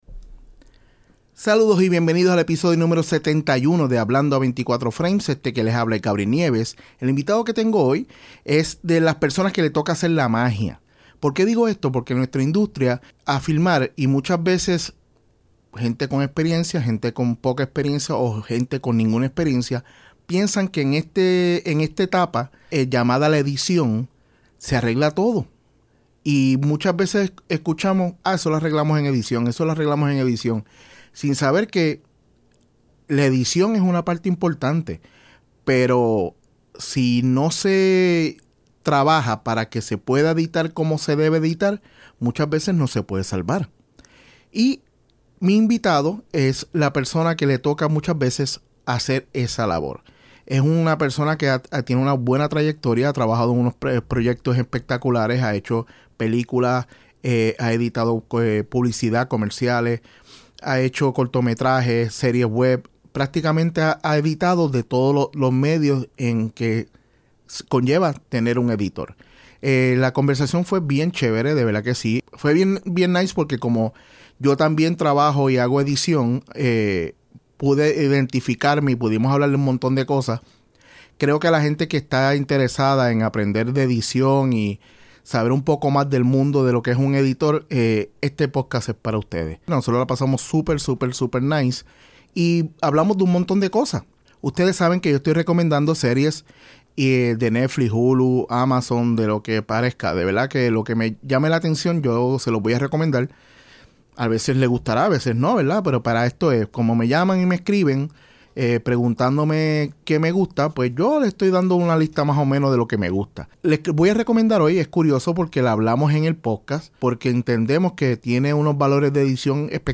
En este episodio converso con un editor con el cual me identifico mucho con su trabajo por que conozco el proceso y el sacrificio del mismo y de lo poco reconocido que es esa labor en los medios.